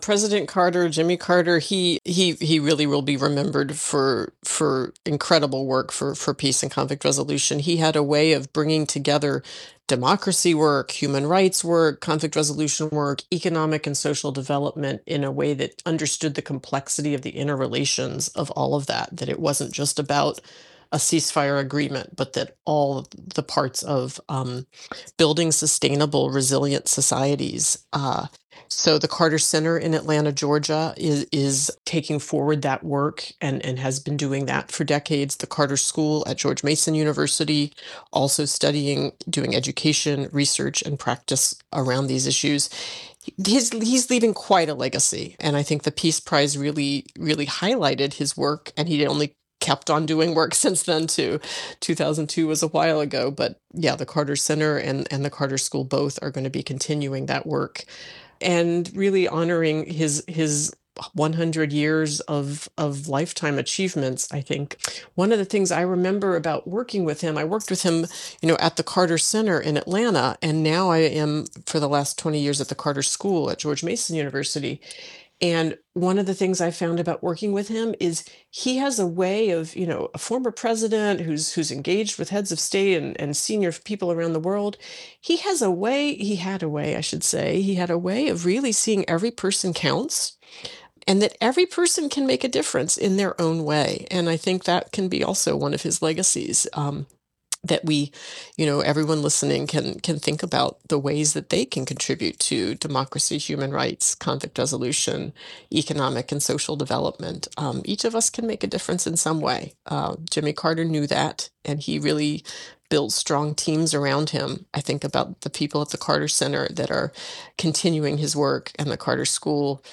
WTOP spoke with historians, political reporters and those who personally knew Carter as the nation mourned his death.